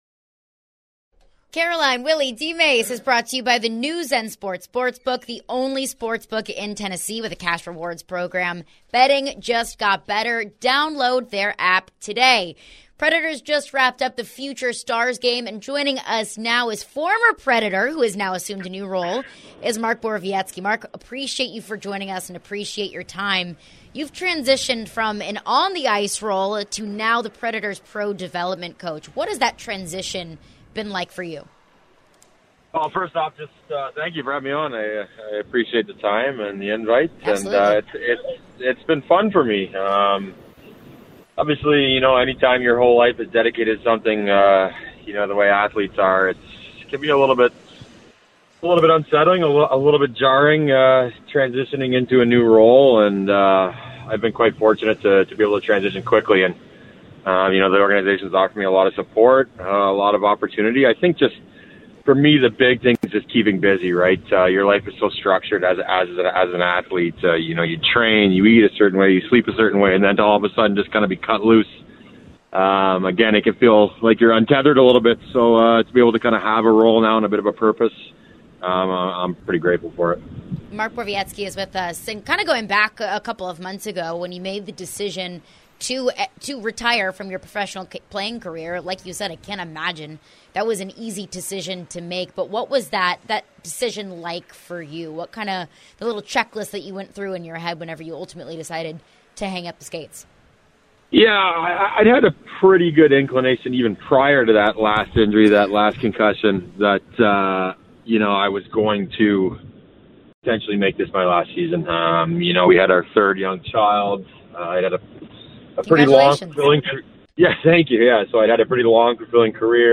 Mark Borowiecki Full Interview (07-06-23)